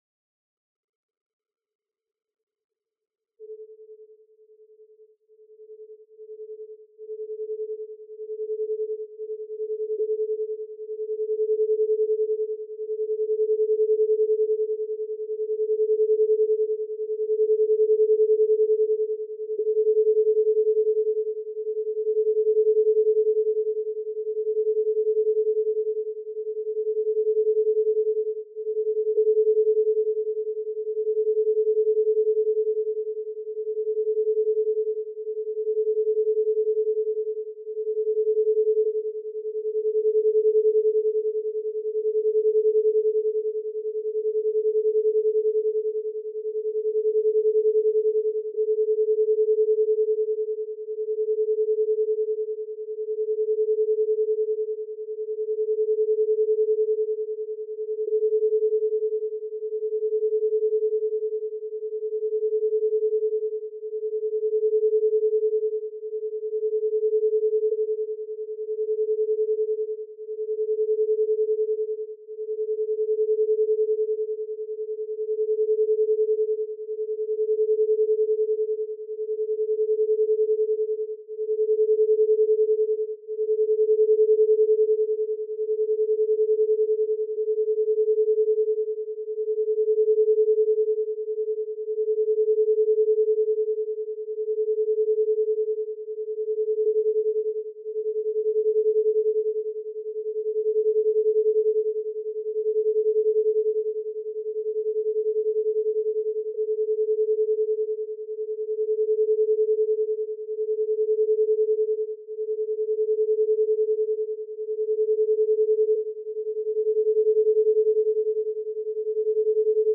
Эта звуковая медитация для чакры третьего глаза создает комфортное пространство для focused relaxation, помогая настроиться на свои эмоции и ощущения.
Техника: Бинауральная ритмика в альфа-диапазоне (8-13 Гц), наложенная на многослойный звуковой ландшафт.
Открытие третьего глаза Раздел: Альфа-волны Размер: 27 MB Длительность: 30 минут Качество: 320 kbps Релиз: 30 ноября 2025 Слушать Скачать Альфа-волны воспроизводятся на разных частотах в каждом ухе, поэтому обязательно слушайте в наушниках.
Звуковой ландшафт: Фон: Глубокий, ровный гул (нидо) или тихая, поющая чаша, создающие ощущение пространства и вибрации. Основной тон: Чистый, плавный синтезаторный пад или флейта, звучащий на частоте, соответствующей чакре третьего глаза.